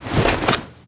gear.wav